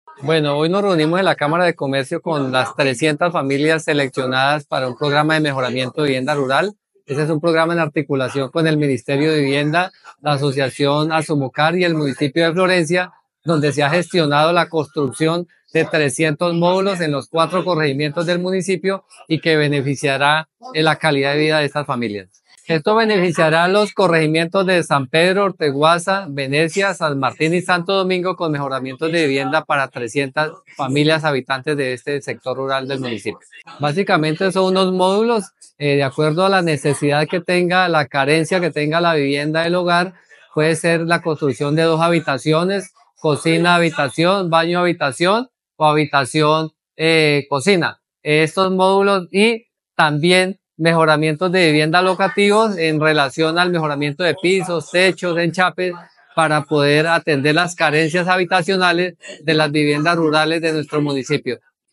El secretario de vivienda municipal, Silvio Lara, explicó que las mejoras se hacen de conformidad con cada beneficiario.